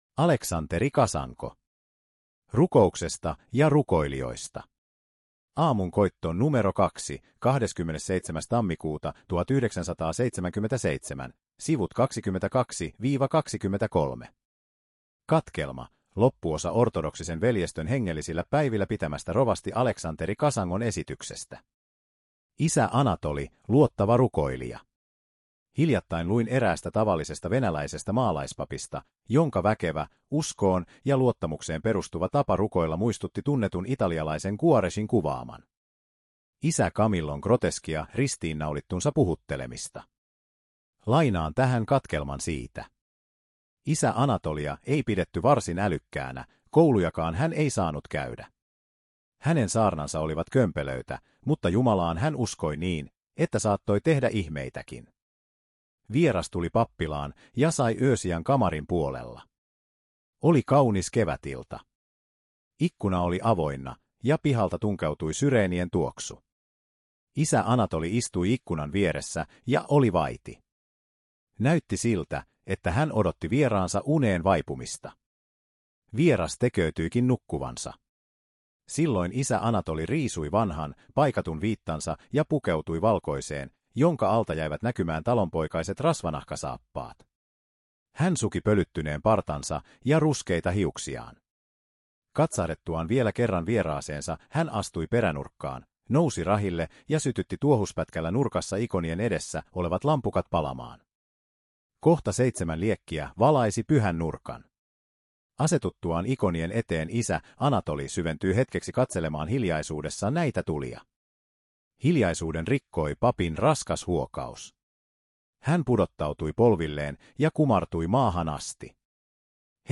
luento